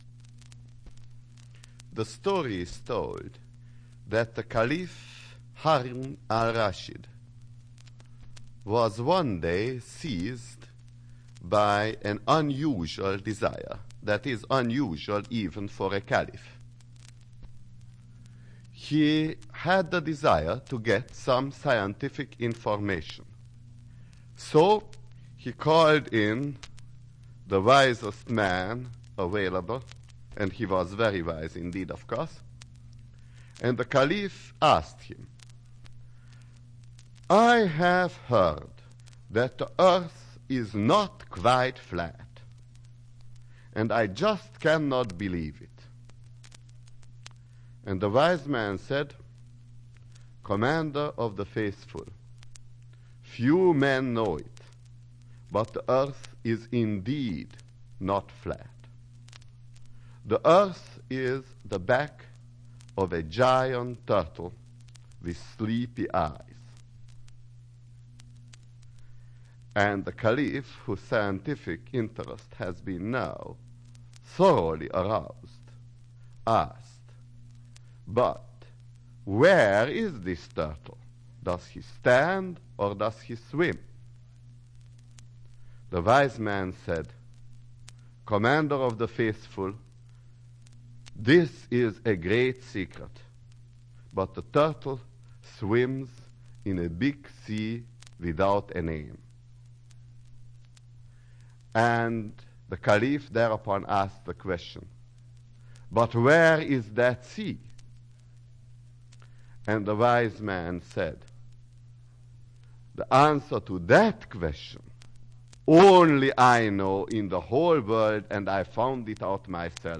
Dr. Edward Teller Physics talks